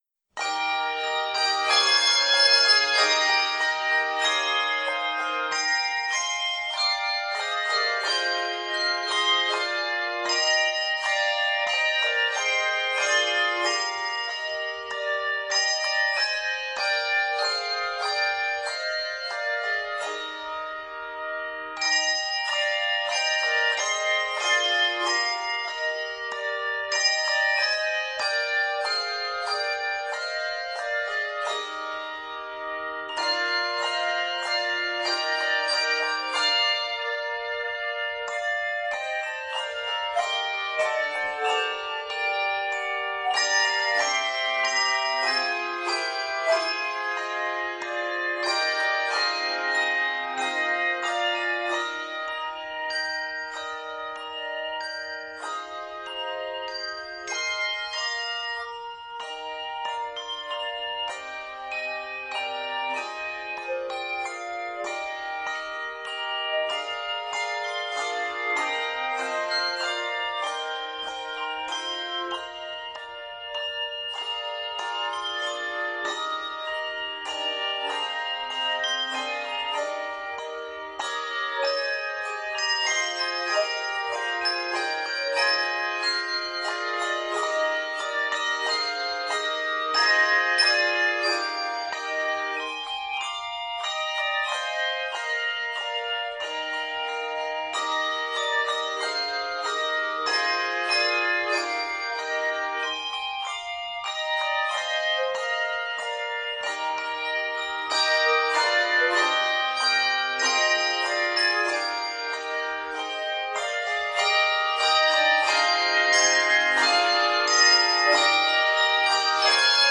written for two to three-octaves